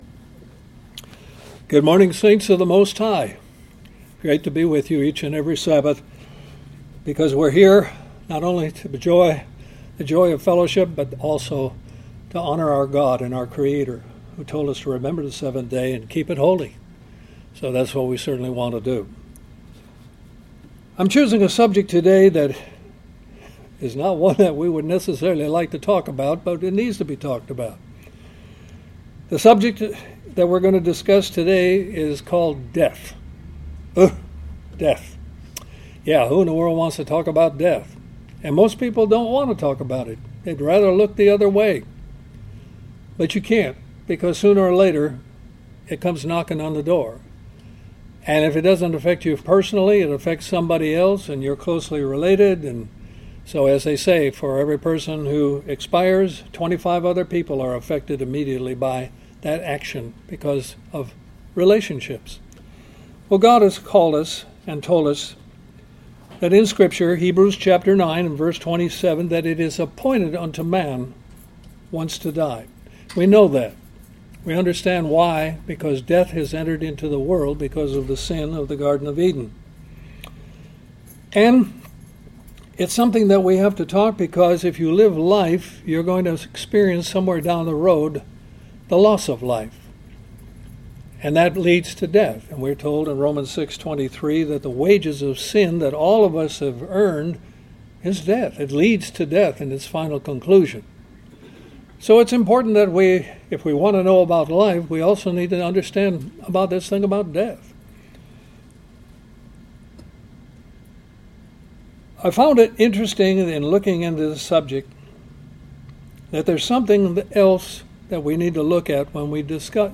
Given in Columbus, GA